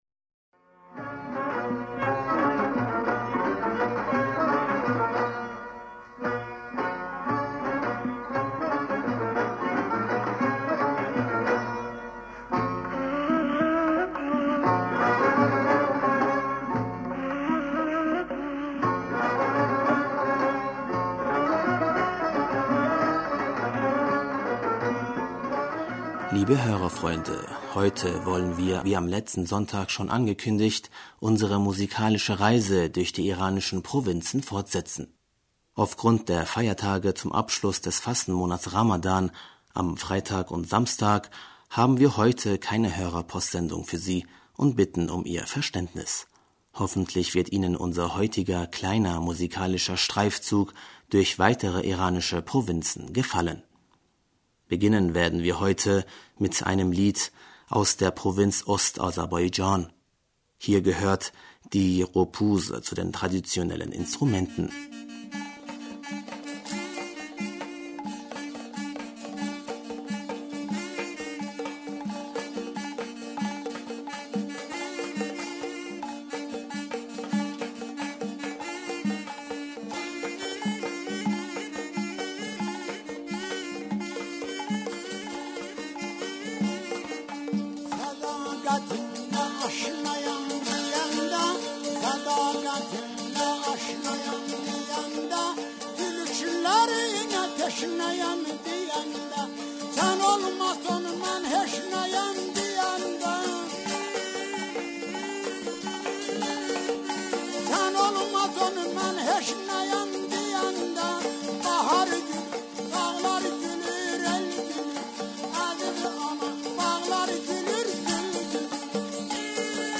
Beginnen werden wir heute mit einem Musikstück aus der Provinz, Ost-Azerbaidschan. Hier gehört die Qopuz zu den traditionellen Instrumenten.
Zum Abschluss hören Sie nun ein Lied aus der westiranischen Provinz Kurdestan.